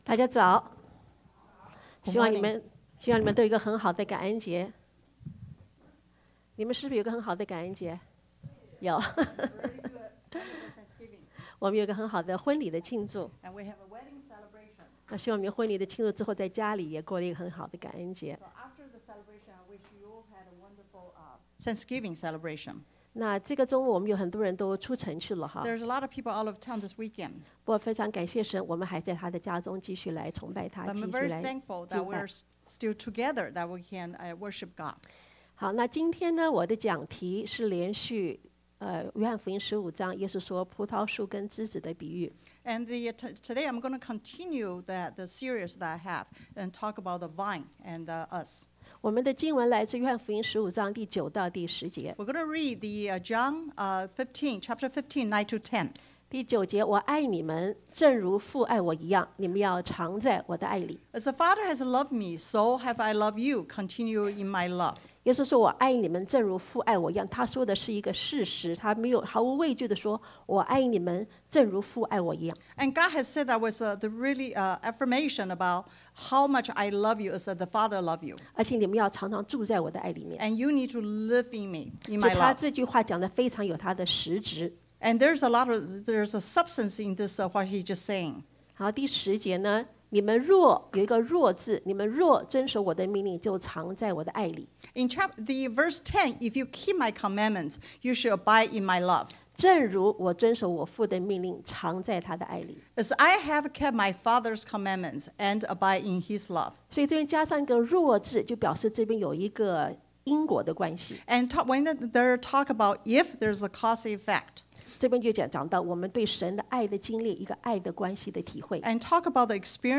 Bilingual Sermon